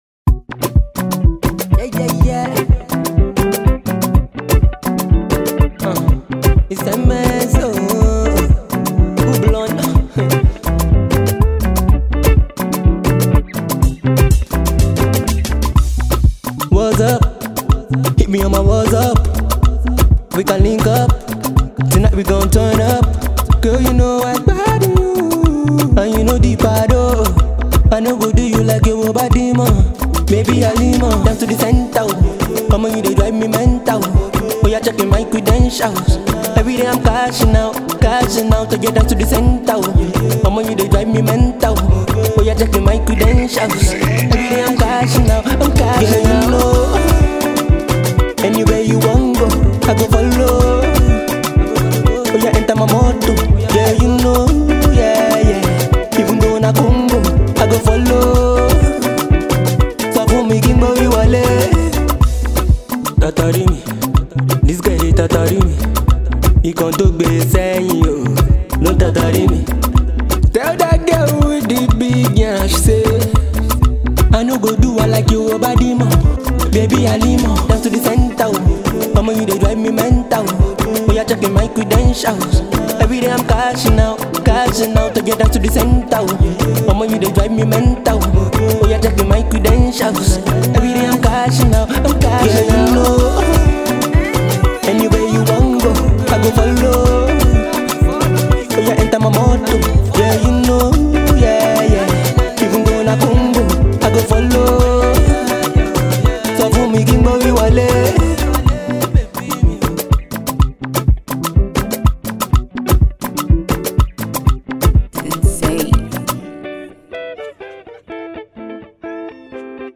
afro infused track
freestyle